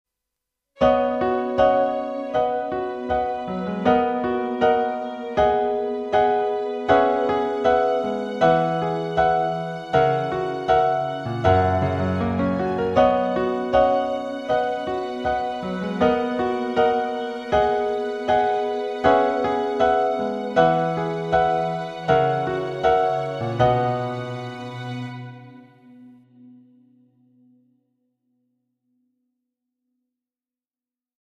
インストゥルメンタル曲